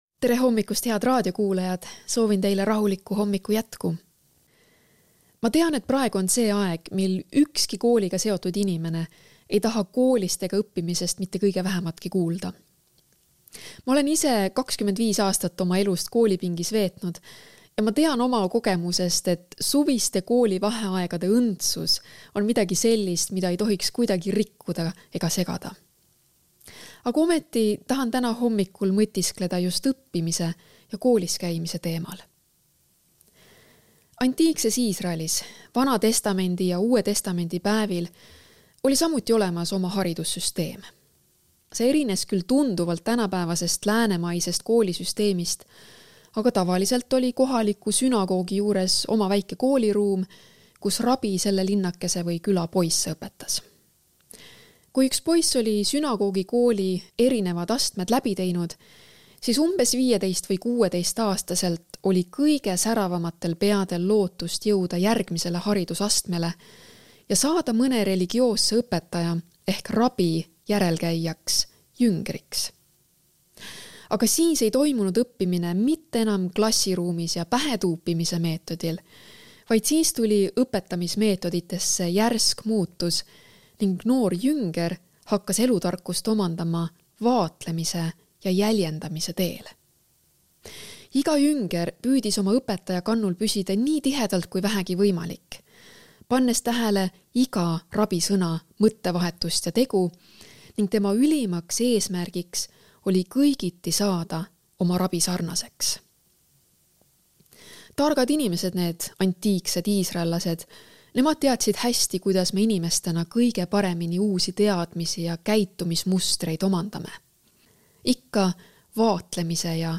hommikupalvus ERR-is 29.06.2024
Hommikupalvused